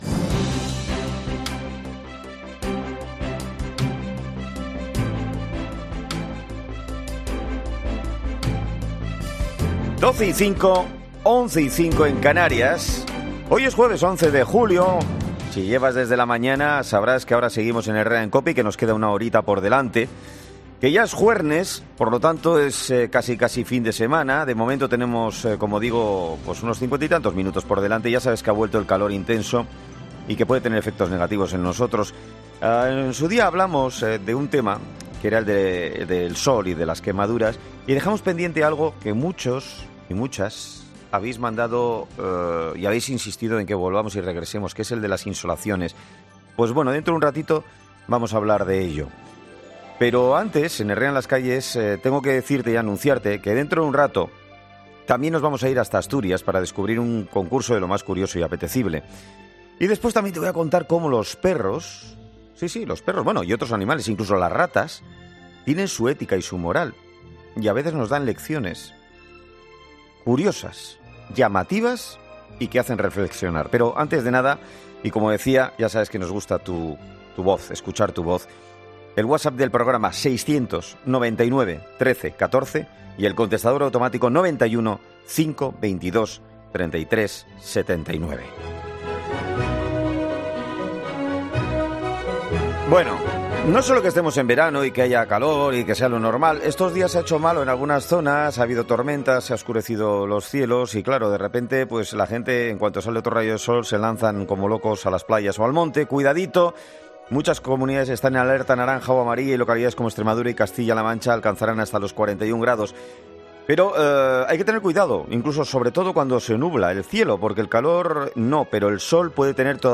Presentado por Carlos Herrera, el comunicador mejor valorado y más seguido de la radio española, es un programa matinal que se emite en COPE, de lunes a viernes,...